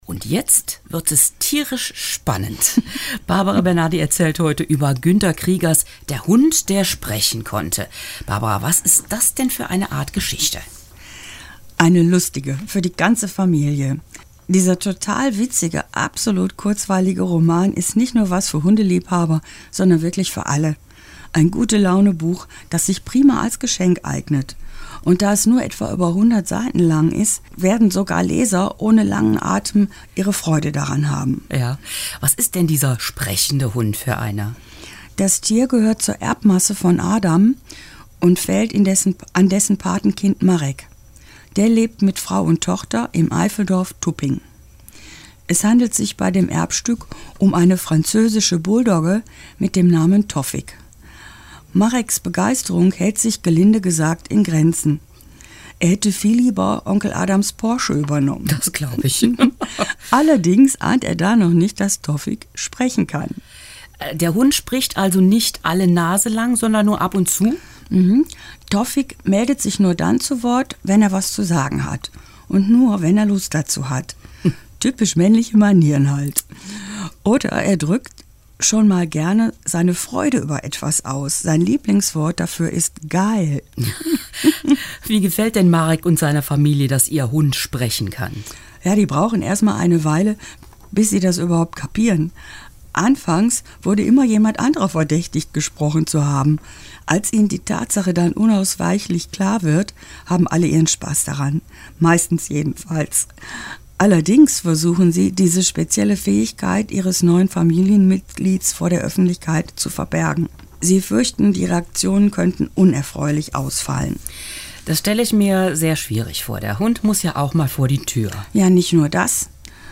Buchvorstellung – „Der Hund, der sprechen konnte“